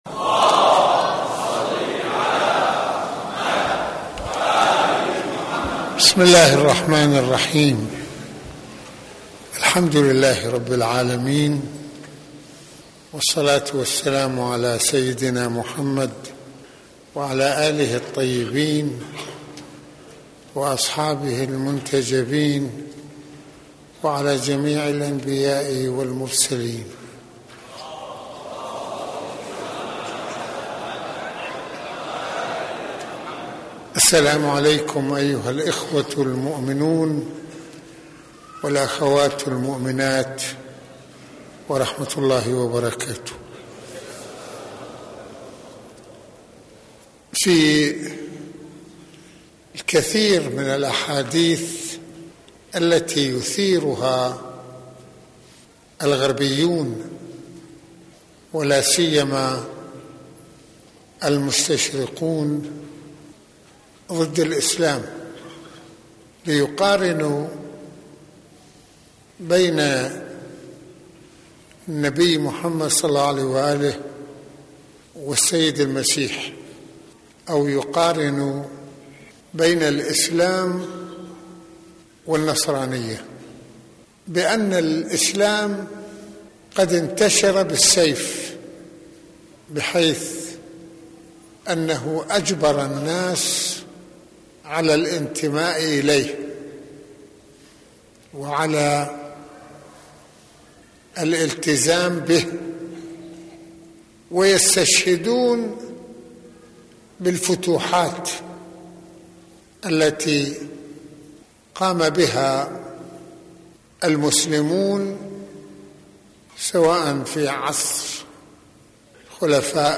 - يتحدث سماحة المرجع السيد محمد حسين فضل الله(رض) في ذكرى عاشوراء عن العلاقة الوطيدة بين الهجرتين النبوية والحسينية وما مثلتاه من حركية الإسلام وما تركتاه من تأثير بارزفي توجيه الكثير من أوضاعنا فحركة الإمام الحسين (ع) هي امتداد لحركة النبي (ص) ودعوته ...
Ashora_Mohadara-2.mp3